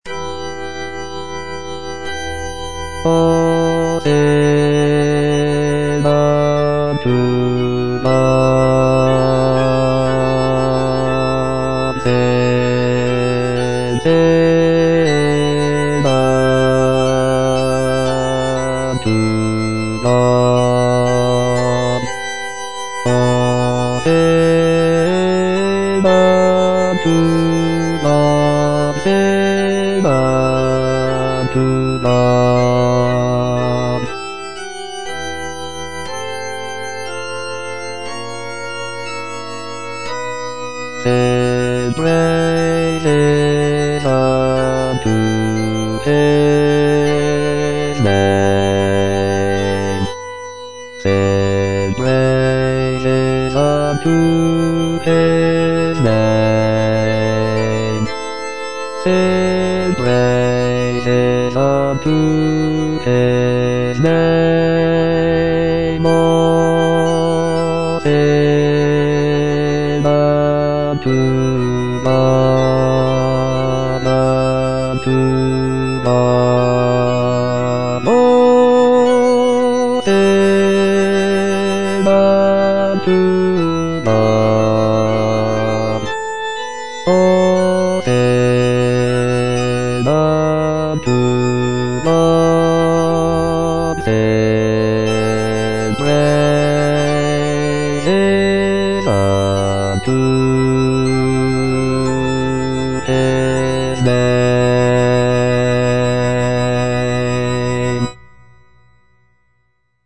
Bass (Voice with metronome) Ads stop
sacred choral work